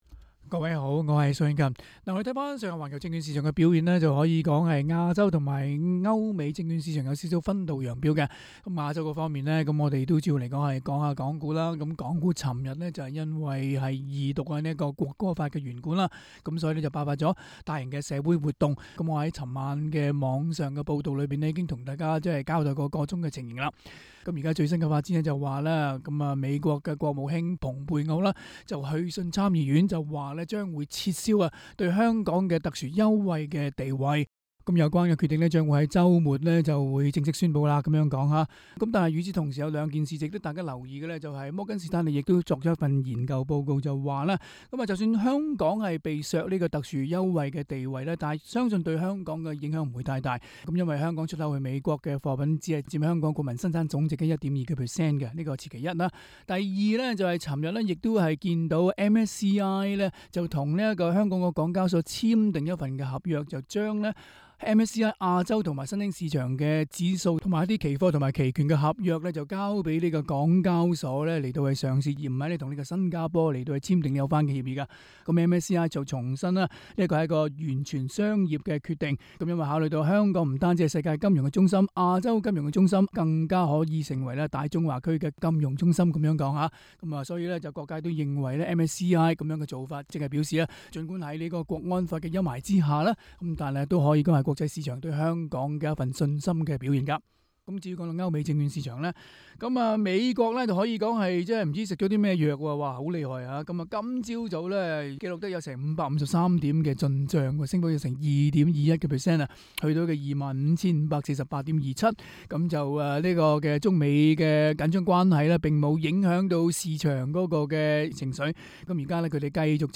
詳情請收聽今天的訪問內容。